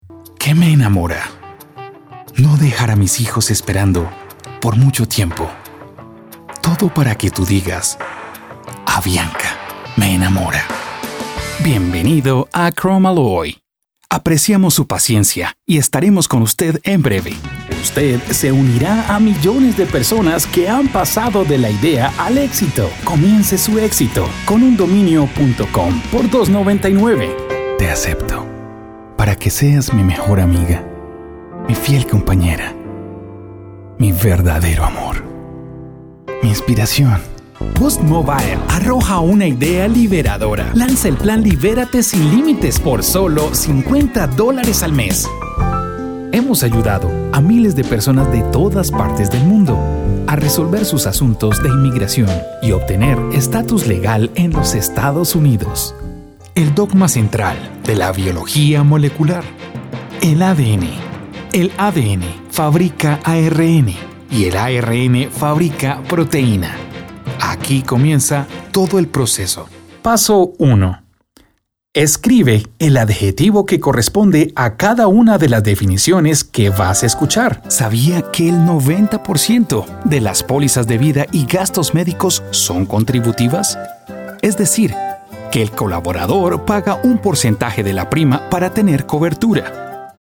I own a professional recording studio and I have several recording microphones such U-47, Sm7B and more.
Español male Spanish voiceover voice over locutor voice actor video audio corporativo jingles masculino voz
kolumbianisch
Sprechprobe: Werbung (Muttersprache):